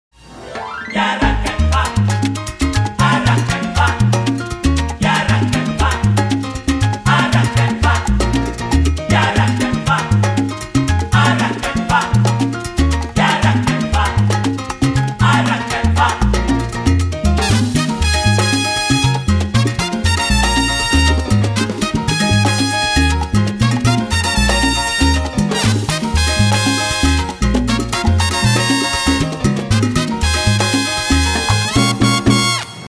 Salsa colombienne ou boogaloo colombien